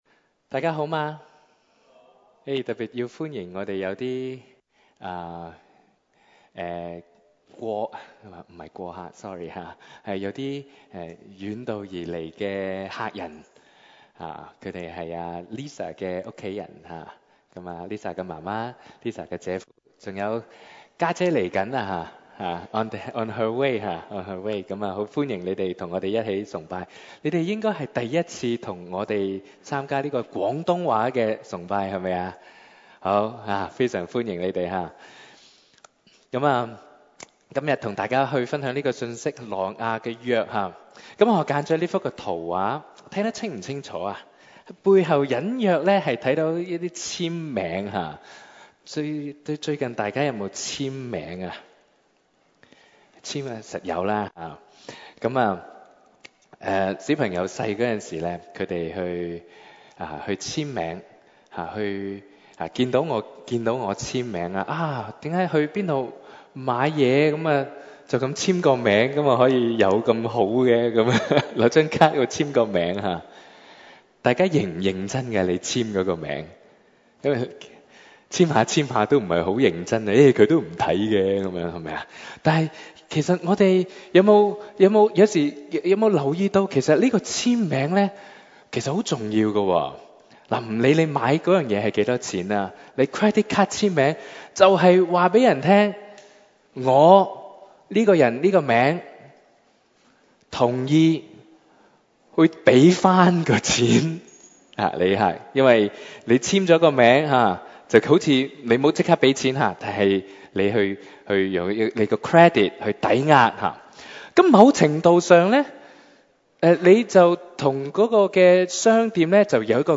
6/26/2022 粵語崇拜講題: 「挪亞之約」